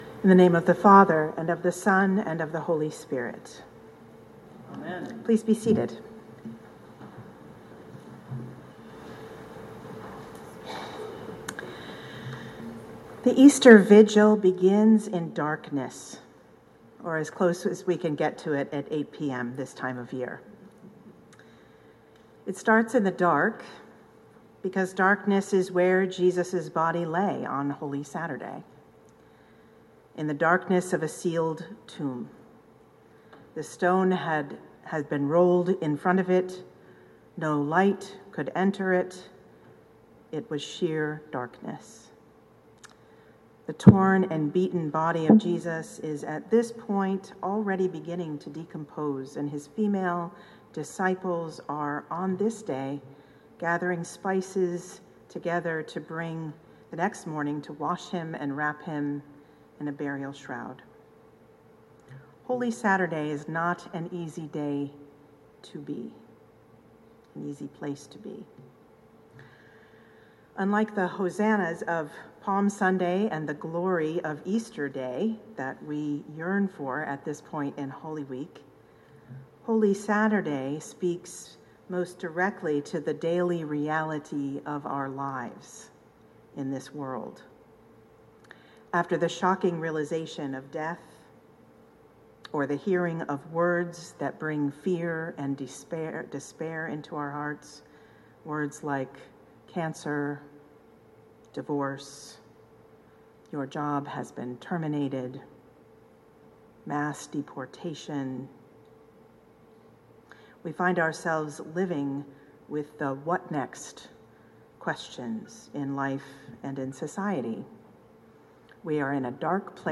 Easter-Vigil-2025.mp3